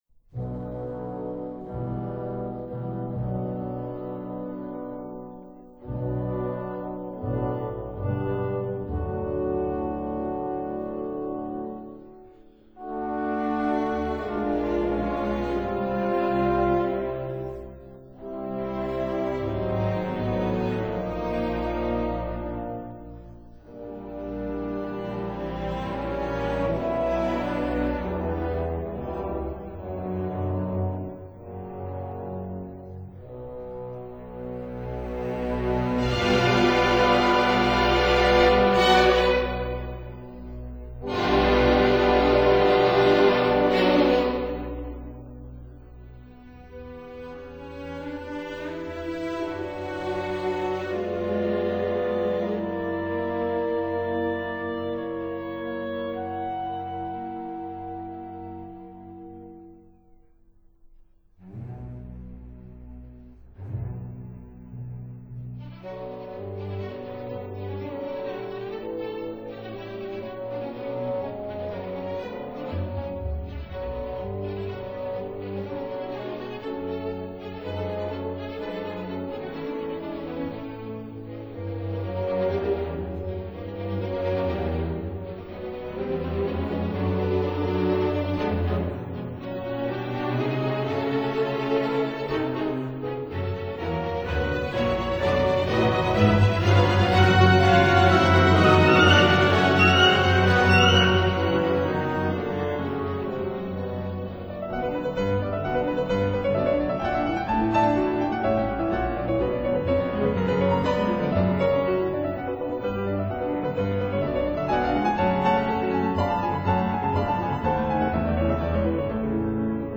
Piano Concerto in B minor